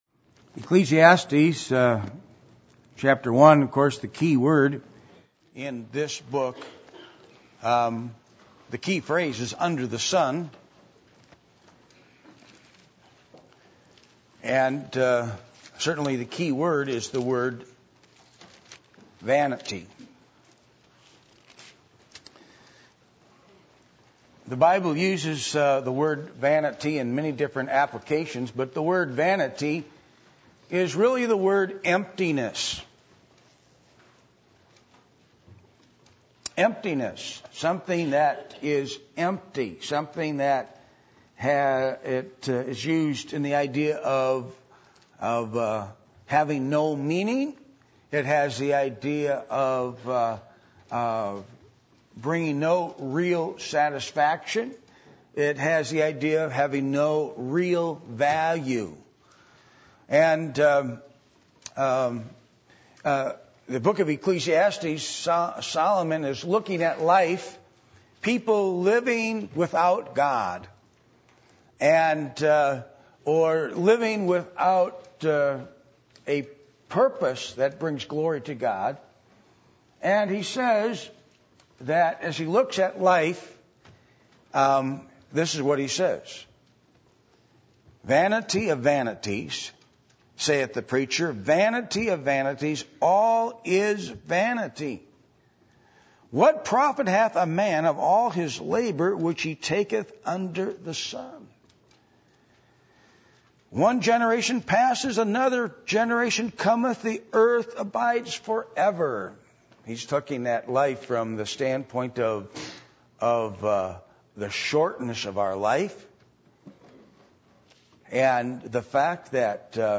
Passage: Ecclesiastes 1:1-18 Service Type: Sunday Morning